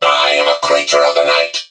mech_mortis_start_vo_03.ogg